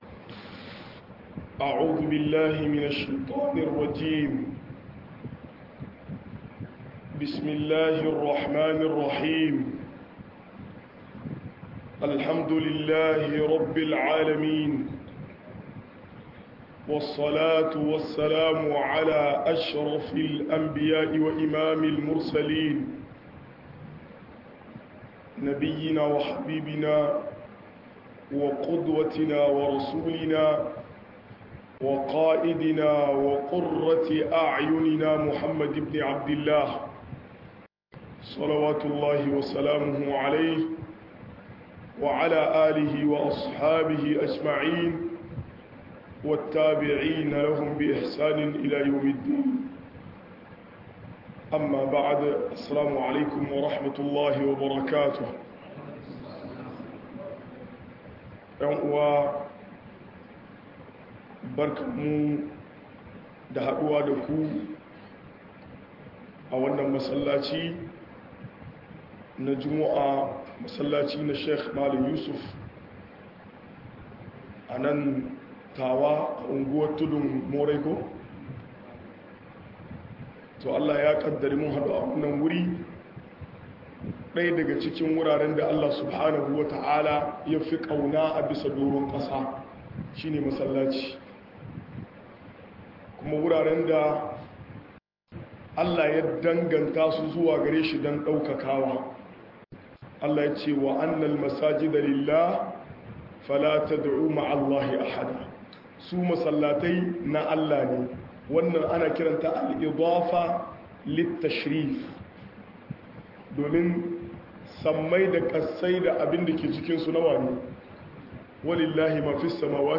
Miyagun akidu da hanyoyin da ake yaɗa su - MUHADARA